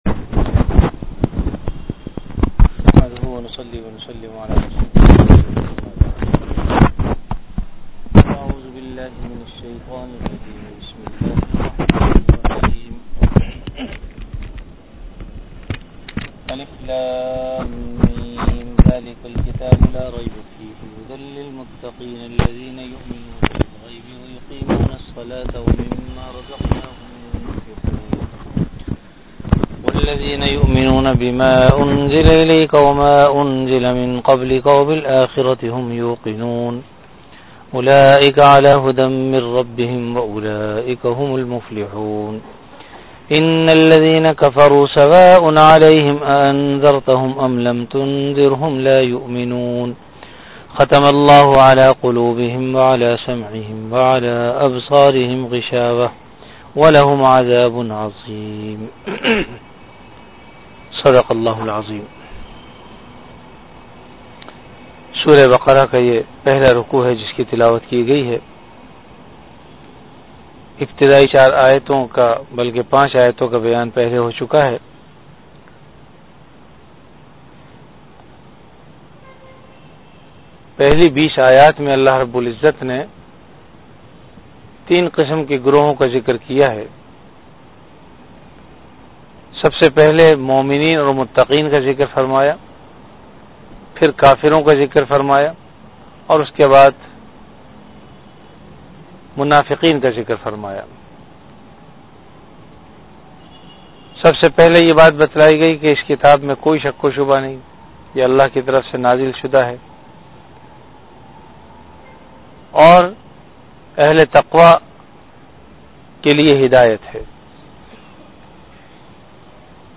Delivered at Jamia Masjid Bait-ul-Mukkaram, Karachi.
Dars-e-quran · Jamia Masjid Bait-ul-Mukkaram, Karachi